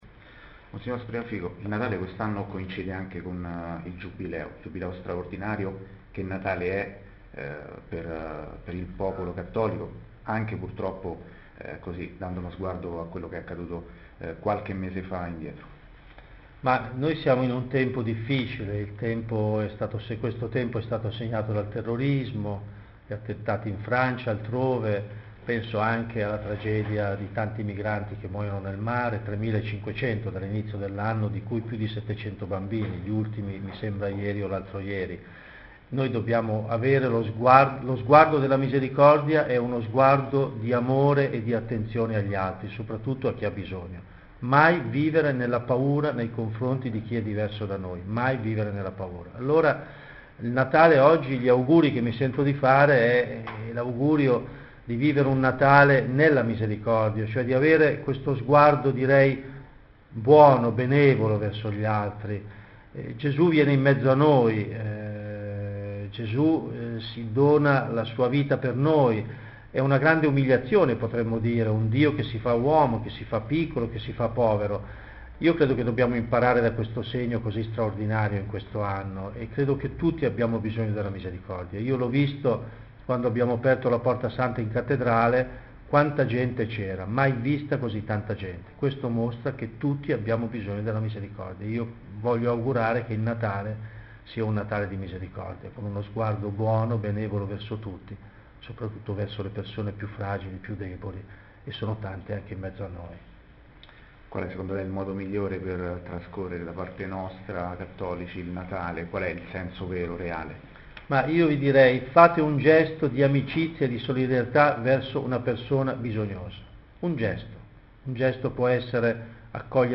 Suggerimenti del Vescovo per il Santo Natale 2015 Dettagli Categoria principale: Video Vescovo Visite: 3064 Segue breve intervento in streaming del Vescovo.